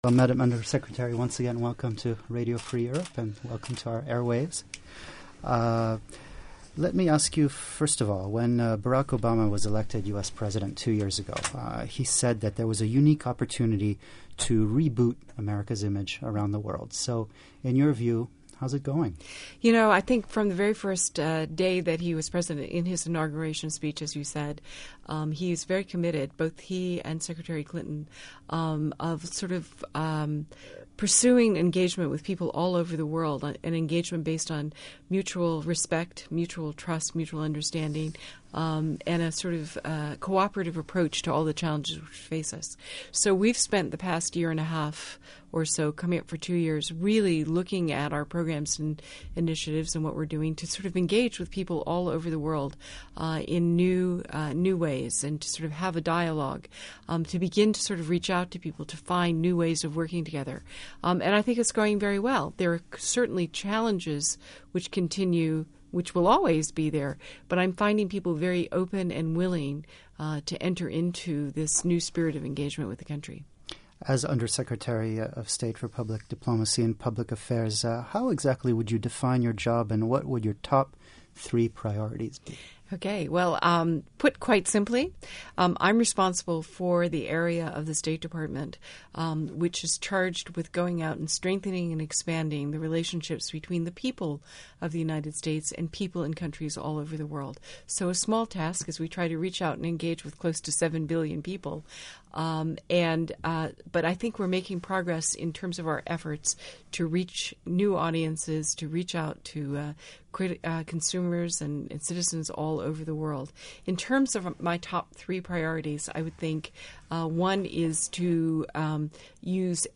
Interview With Judith McHale, U.S. undersecretary of state for public diplomacy and public affairs